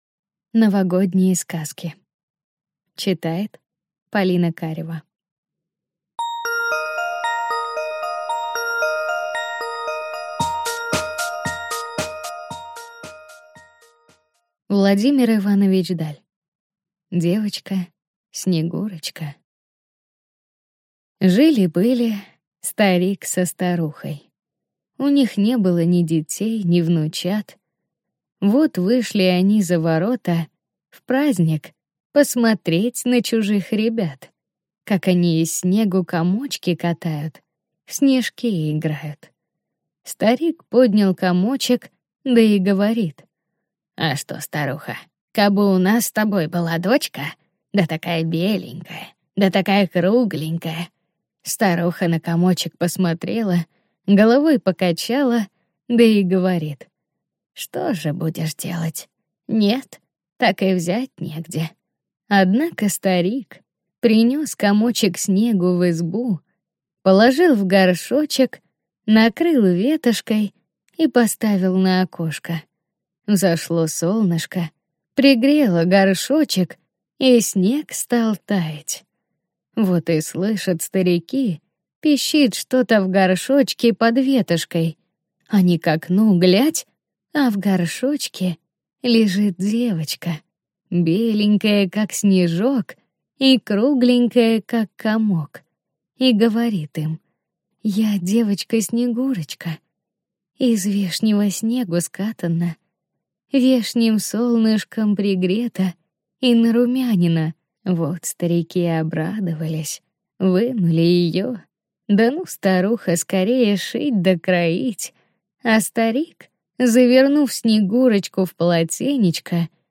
Aудиокнига Новогодние сказки